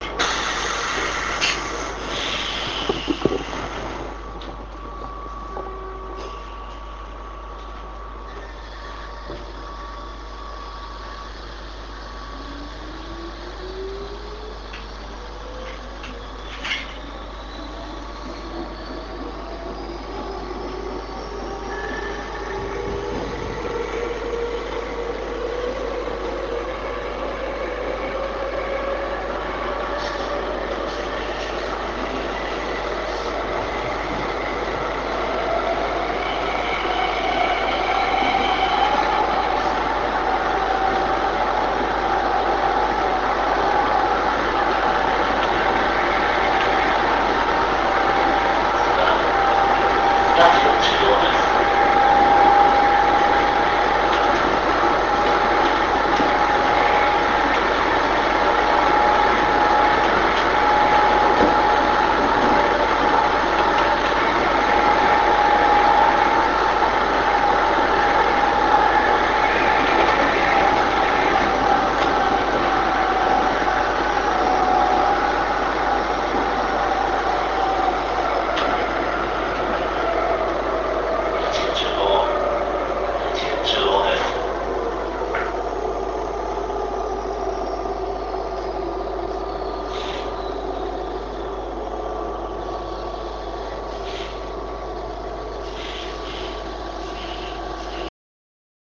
５０００系の音
営団５０００系（始発駅発車直後）走行音 村上→八千代中央 RealAudio形式 202kb
始発（長時間停車）駅発車直後は少々独特な音を発します。